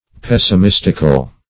Pessimistical \Pes`si*mis"tic*al\, a.
pessimistical.mp3